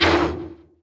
Index of /App/sound/monster/spite_ghost